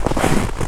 STEPS Snow, Walk 17-dithered.wav